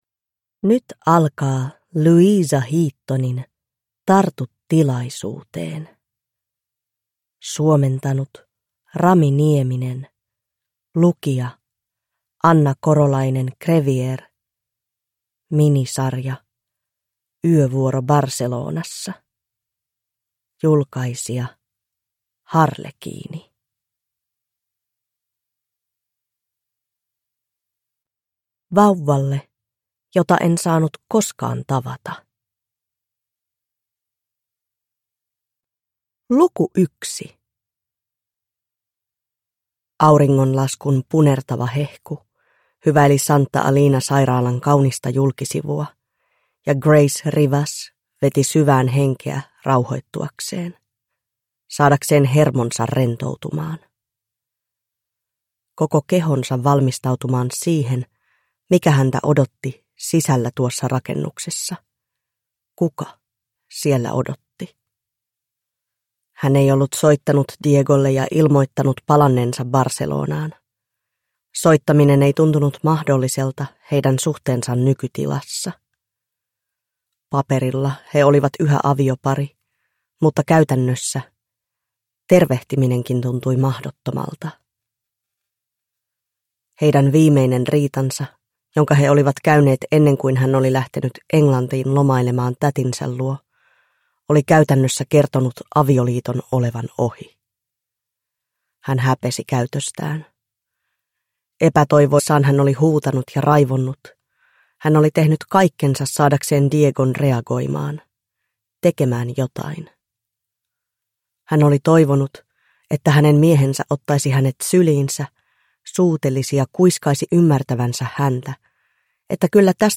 Tartu tilaisuuteen (ljudbok) av Louisa Heaton